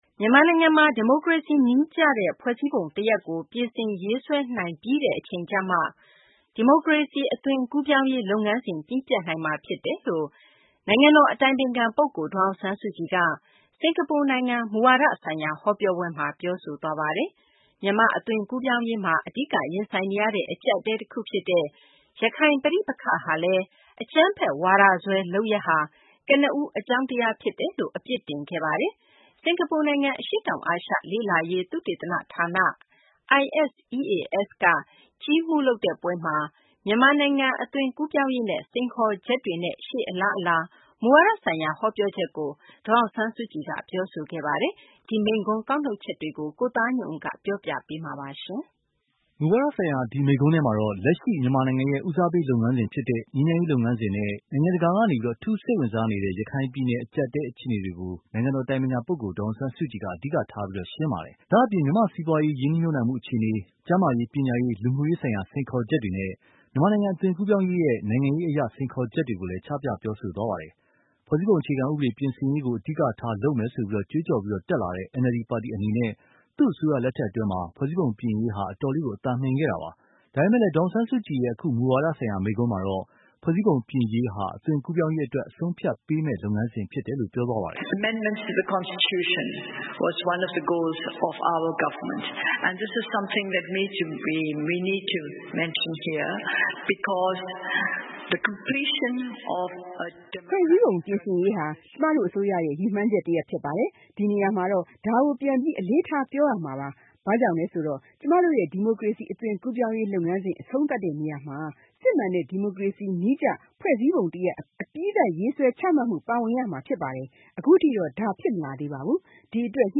ဒေါ်စု စင်ကာပူမိန့်ခွန်း ကောက်နုတ်ချက်